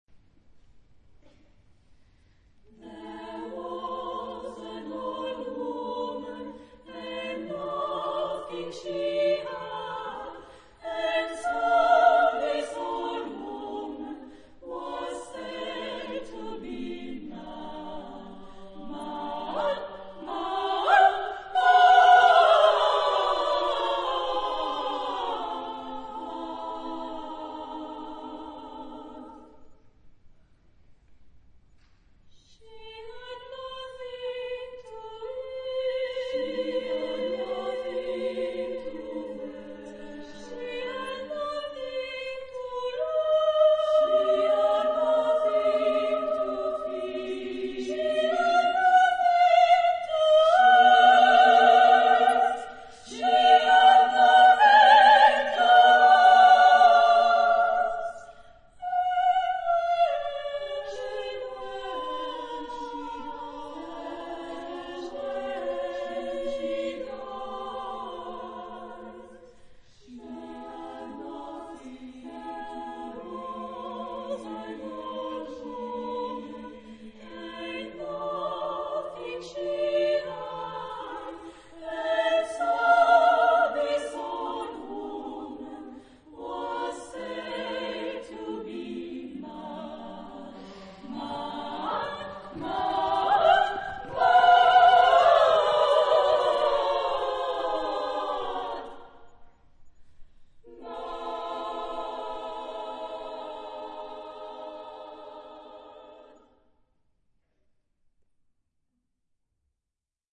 Genre-Style-Forme : Cycle ; Pièce chorale ; Profane
Tonalité : libre